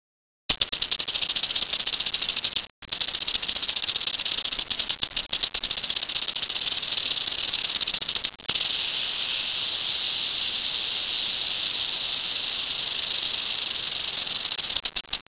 rattlesnakesound.AU